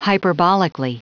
Prononciation du mot hyperbolically en anglais (fichier audio)
Prononciation du mot : hyperbolically